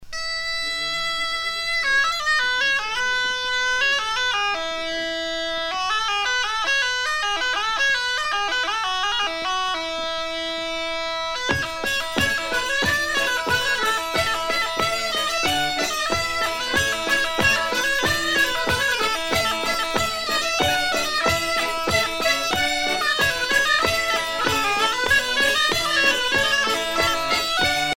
danse : scottich sept pas
Pièce musicale éditée